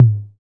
Index of /90_sSampleCDs/Sound & Vision - Gigapack I CD 1 (Roland)/KIT_DANCE 1-16/KIT_Dance-Kit 15
TOM TM052.wav